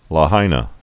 (lä-hīnə)